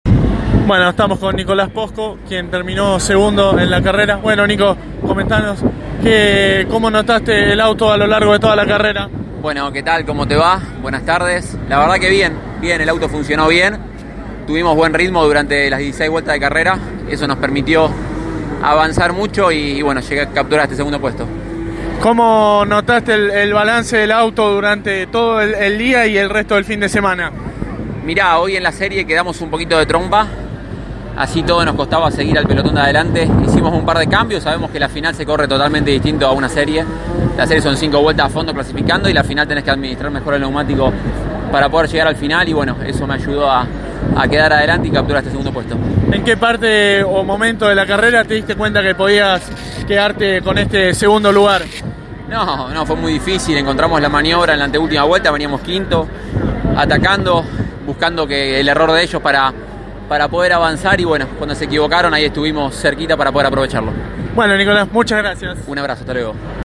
Por ello, tras concluida la final de Clase 2, dialogó con los protagonistas que aquí podrás escuchar.